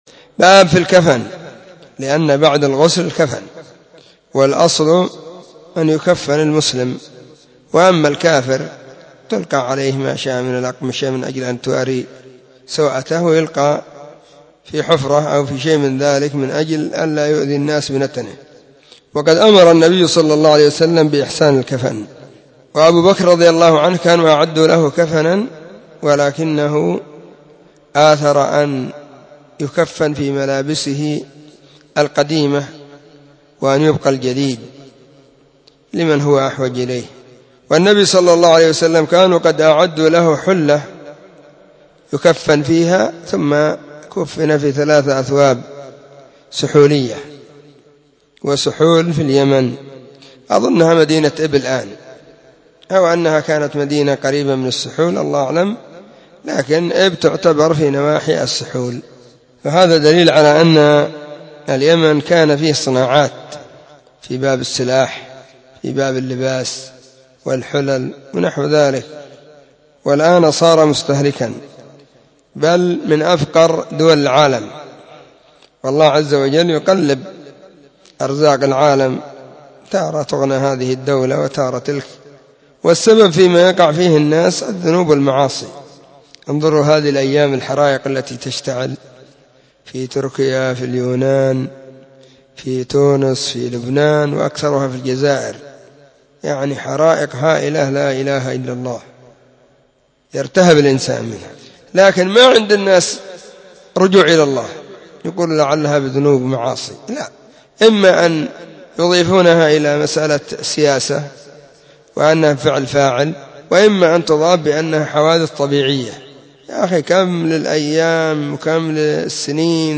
💢نصيحة قيمة بعنوان💢 *🔰المعاصي والذنوب وأثرها في تأخر الشعوب*